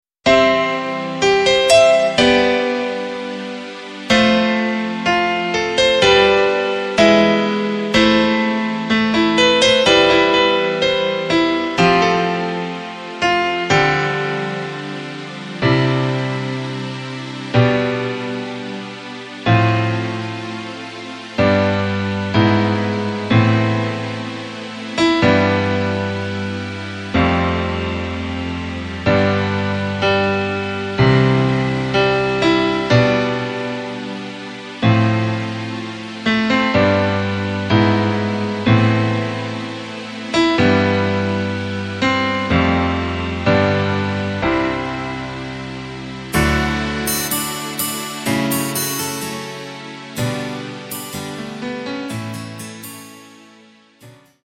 Takt:          4/4
Tempo:         125.00
Tonart:            C
Playback mp3 Demo